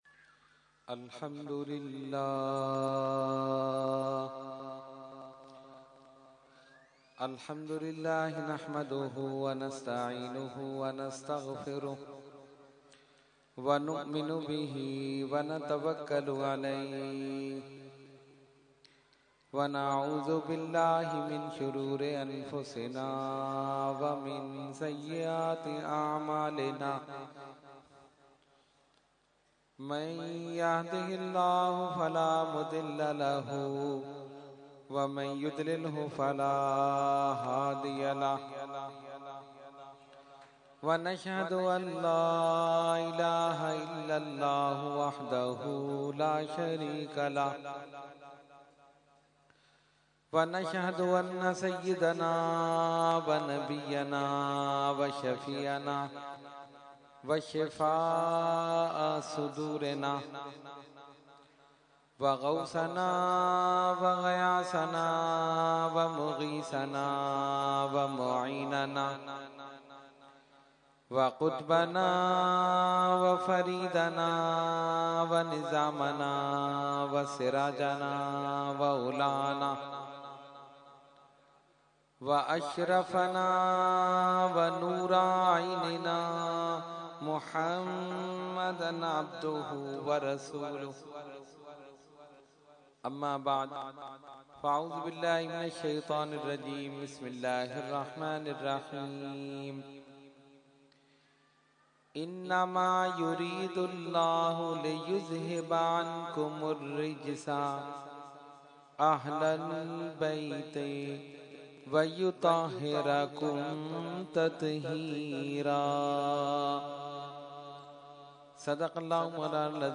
Category : Speech | Language : UrduEvent : Muharram ul Haram 2014